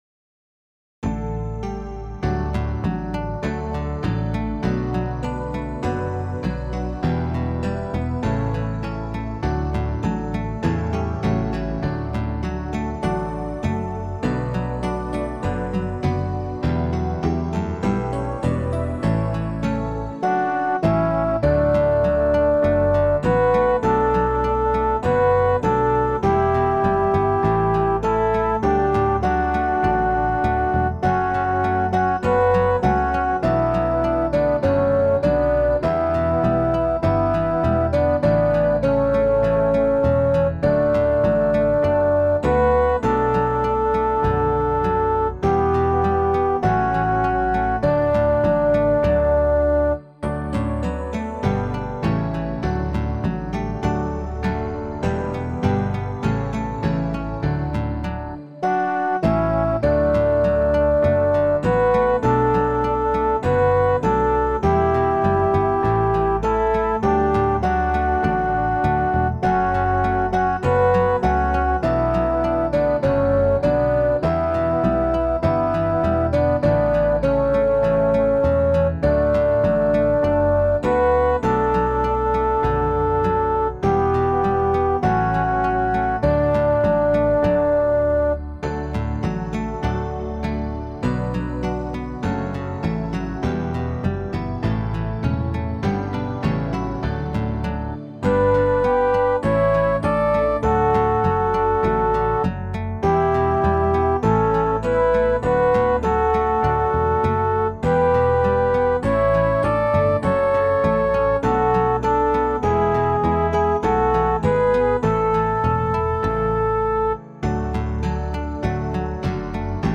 MP3 instrumental